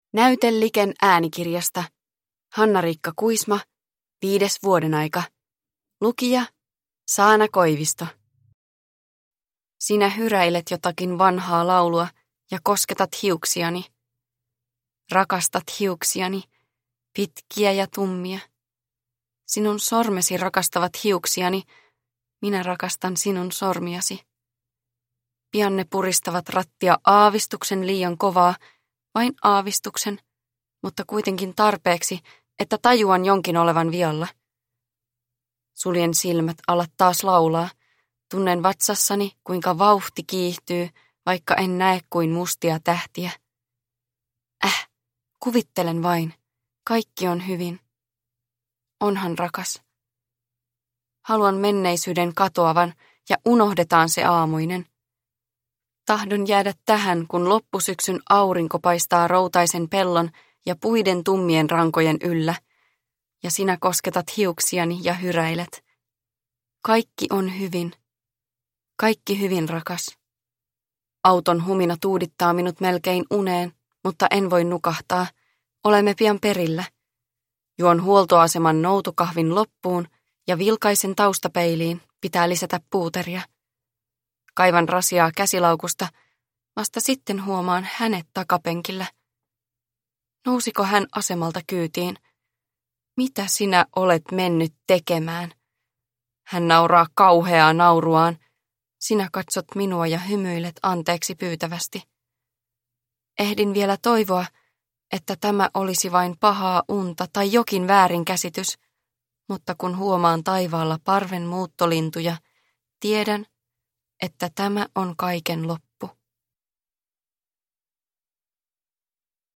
Viides vuodenaika – Ljudbok – Laddas ner